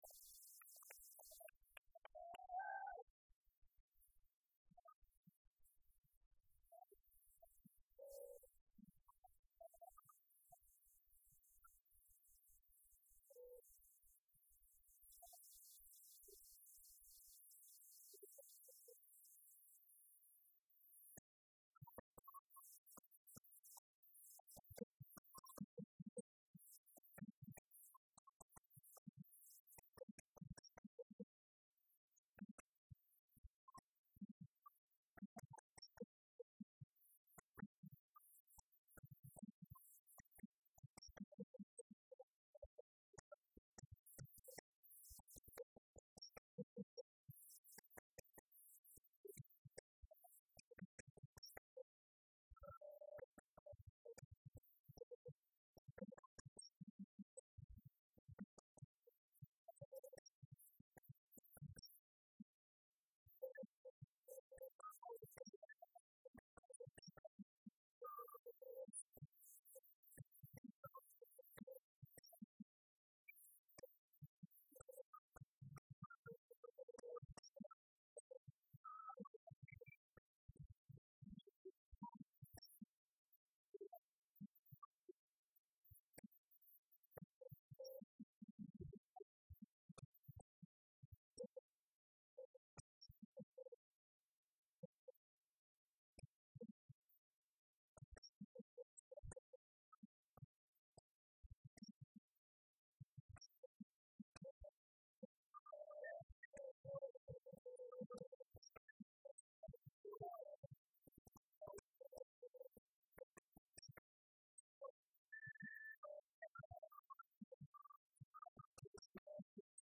O2 Arena
Lineage: Audio - AUD (Sony ECM-717 + Sony PCM-M10)